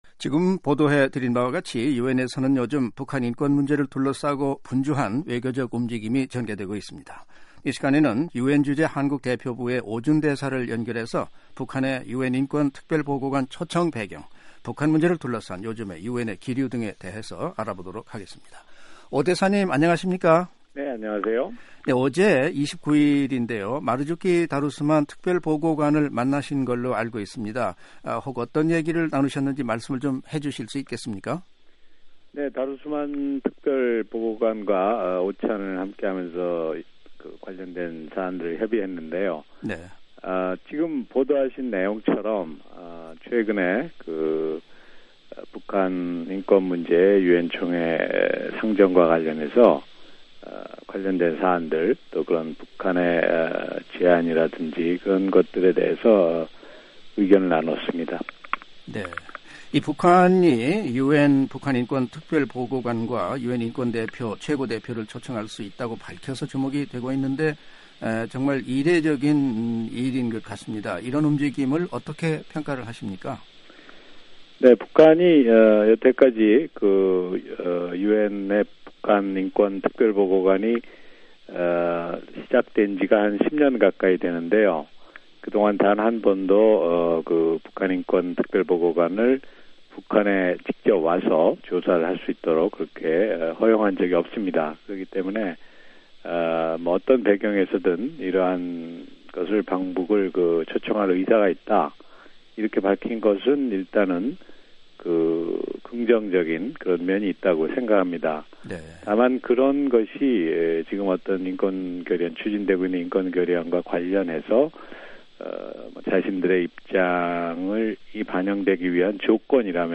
[인터뷰: 유엔 한국대표부 오준 대사] "북한, 유엔대표부 내 인권 관련 인원 보강"
[인터뷰 오디오 듣기] 유엔주재 한국대표부 오준 대사